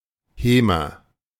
Hemer (German pronunciation: [ˈheːmɐ]